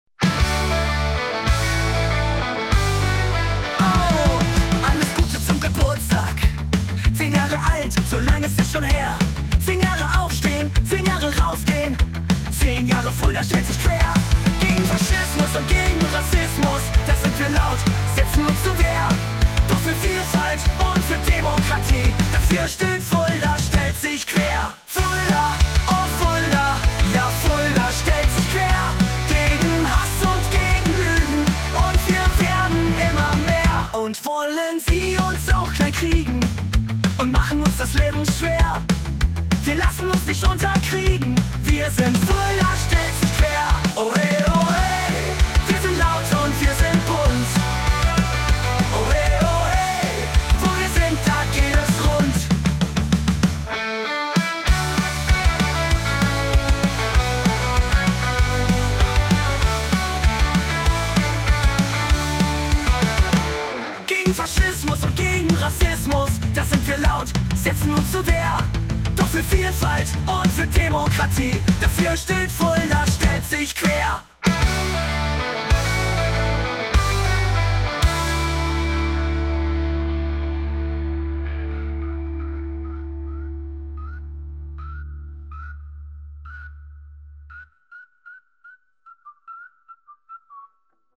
audio_punk.mp3